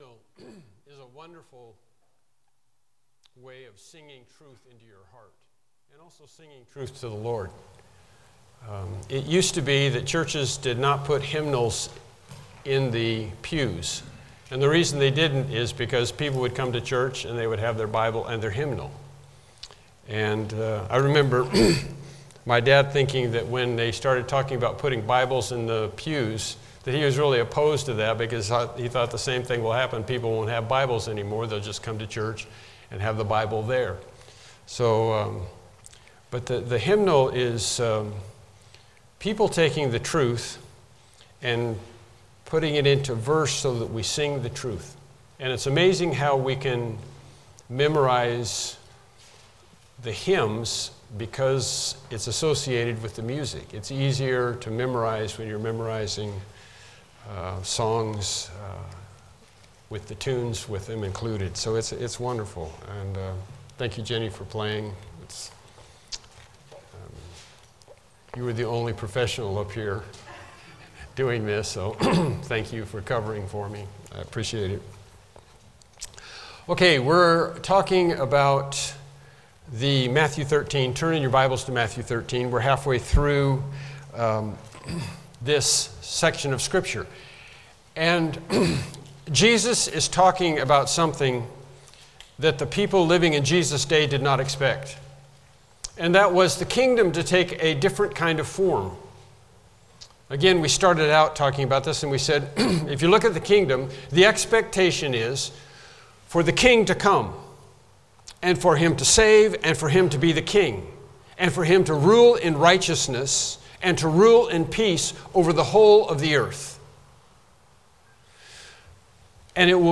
Evening Sermons , The Study of Things to Come Service Type: Evening Worship Service « The Commandment Question Lesson 11